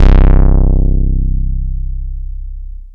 ANALOG 2 1.wav